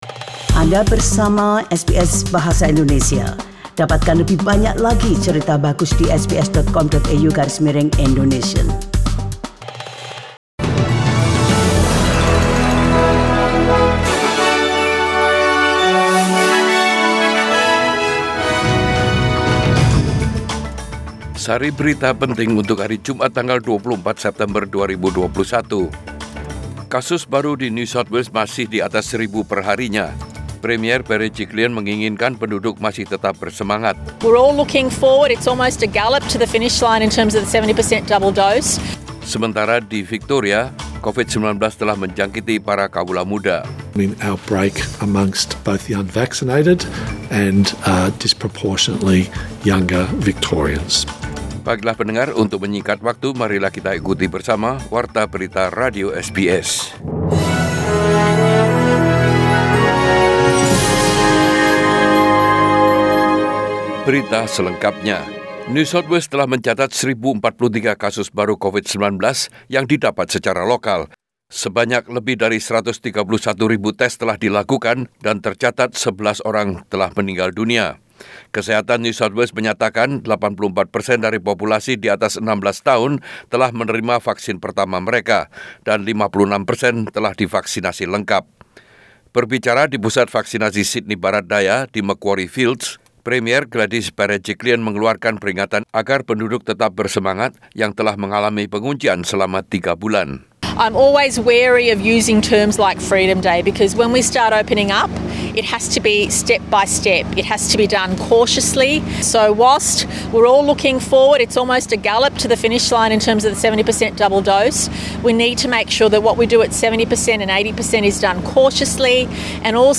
SBS Radio News in Bahasa Indonesia - 24 September 2021
Warta Berita Radio SBS Program Bahasa Indonesia Source: SBS